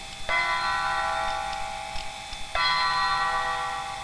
Dong.wav